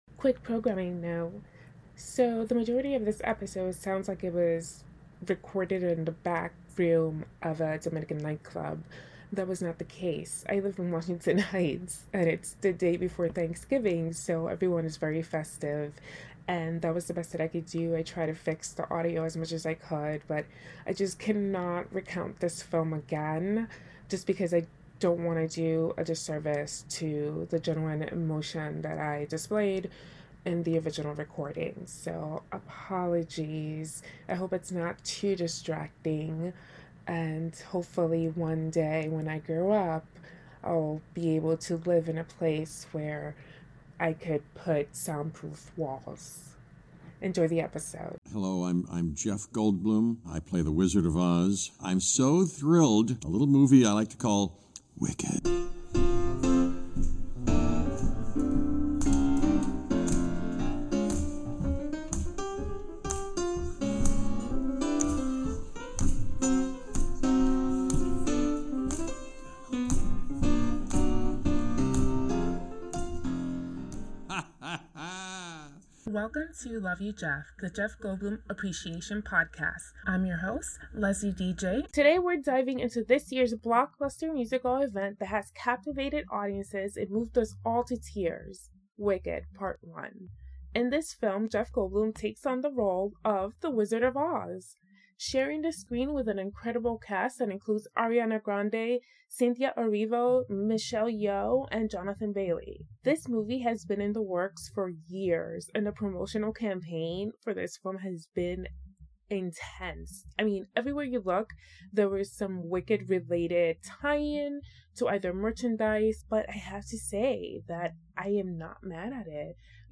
Disclaimer: Apologies on behalf of my neighbors who were playing music in the background. Hopefully, it is not too distracting.